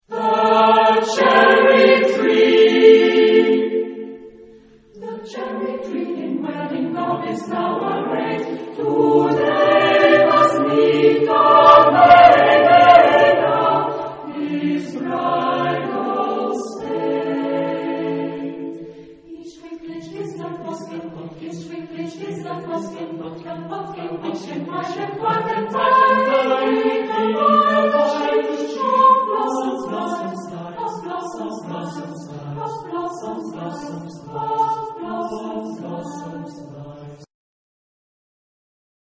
Epoque: 20th century
Genre-Style-Form: Secular ; Vocal piece ; Madrigal
Mood of the piece: contrasted
Type of Choir: SATB  (4 mixed voices )
Tonality: D minor